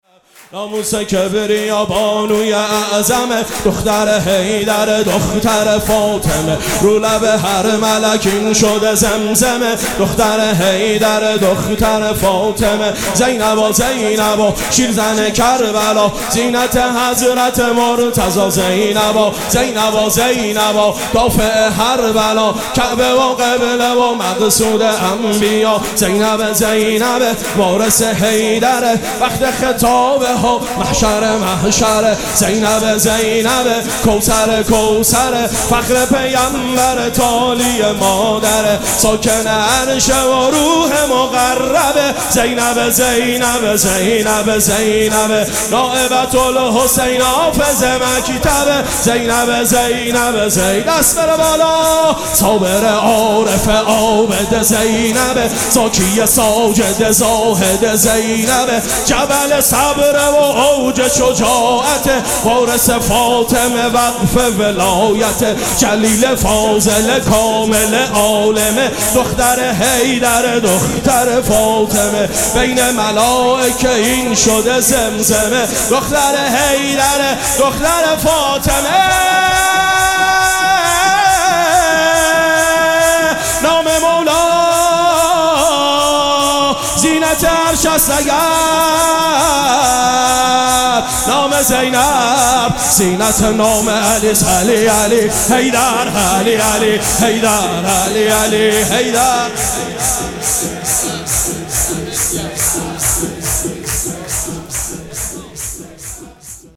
مراسم جشن ولادت حضرت زینب سلام‌الله‌علیها
سرود
مداح